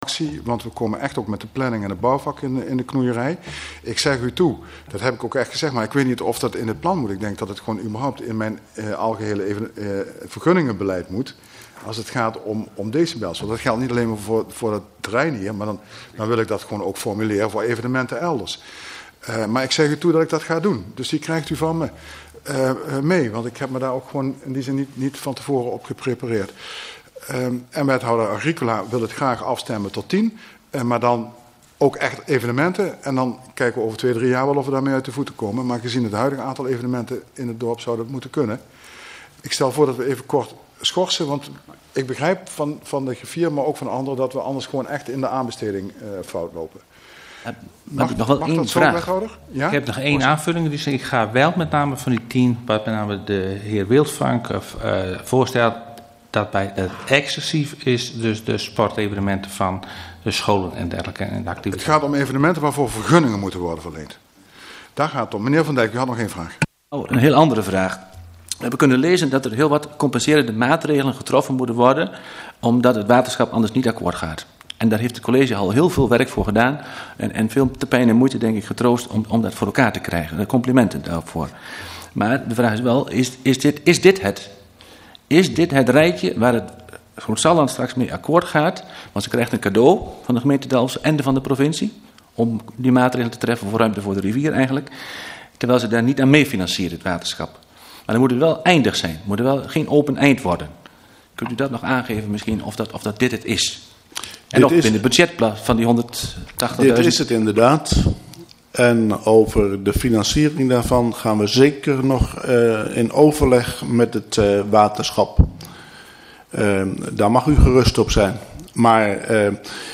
Gemeenteraad 18 juni 2012 19:00:00, Gemeente Dalfsen